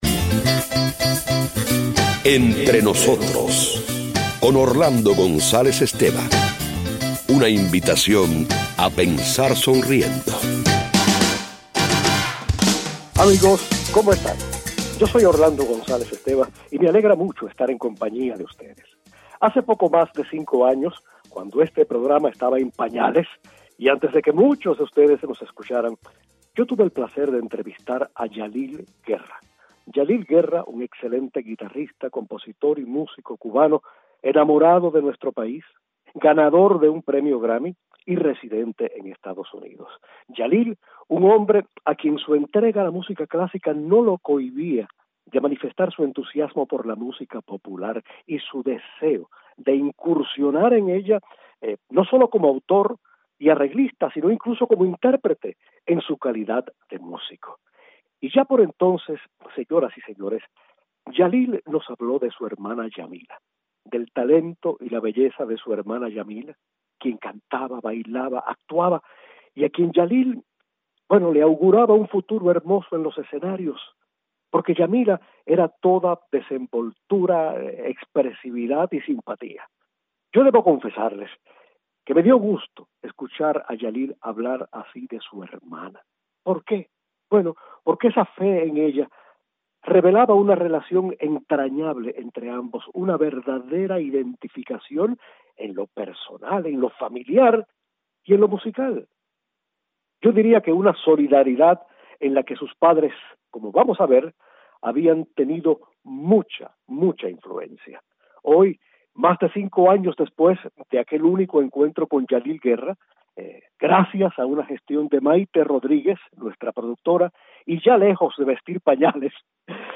Hoy conversamos con la cantante, una artista encantadora cuya carrera la ha llevado a España, Francia, México, Estados Unidos y cuyas grabaciones demuestran hasta qué punto vive enamorada de su país.